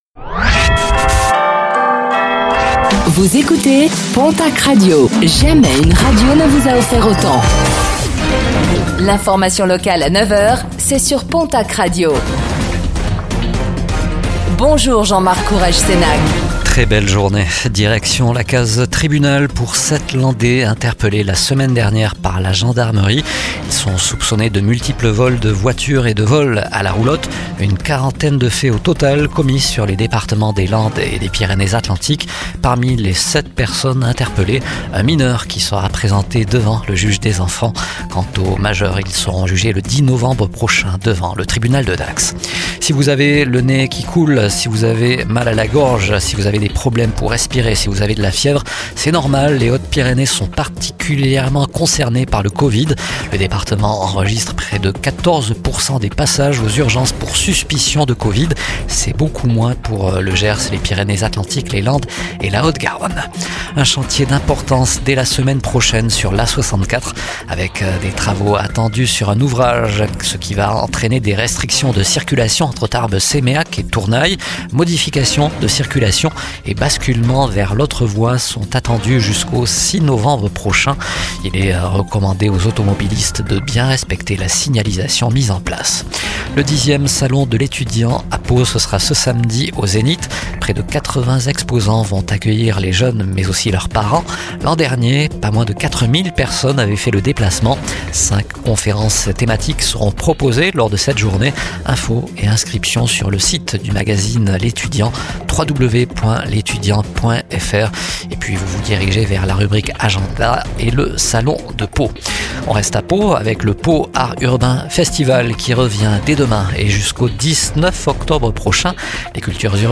09:05 Écouter le podcast Télécharger le podcast Réécoutez le flash d'information locale de ce jeudi 09 octobre 2025